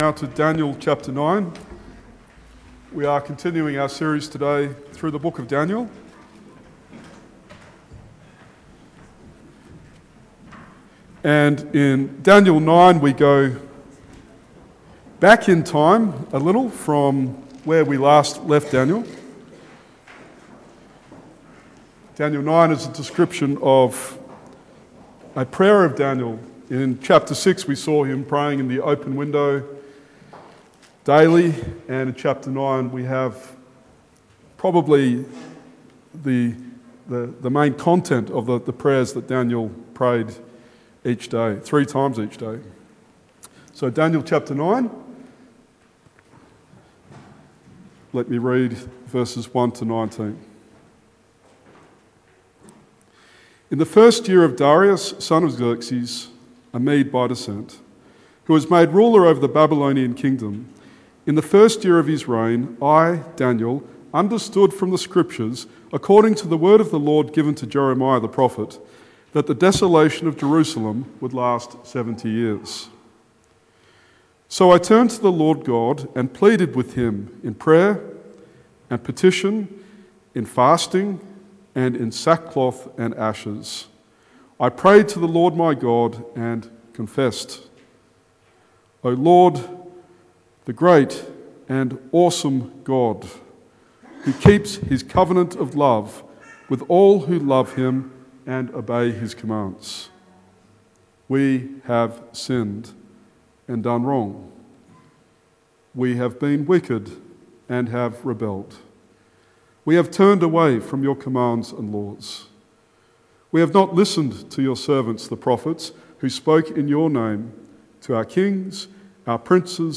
Daniel 9:1-19 Sermon